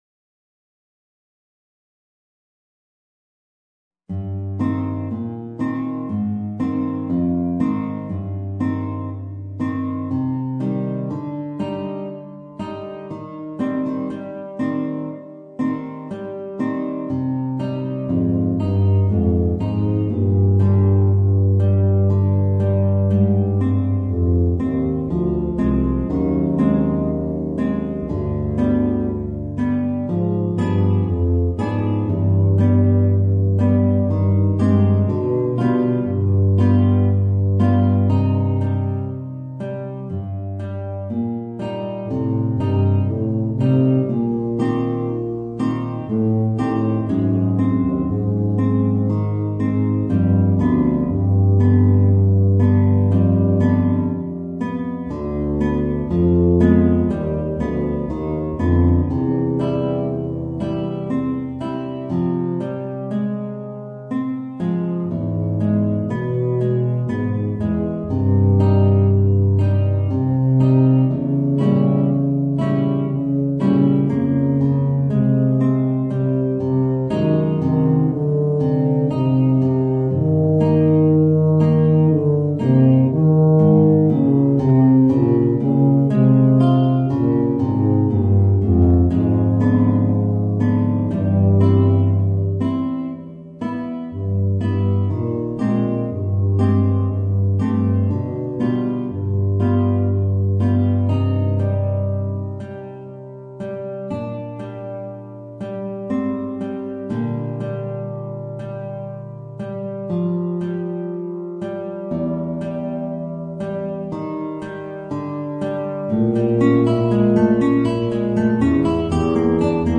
Voicing: Tuba and Guitar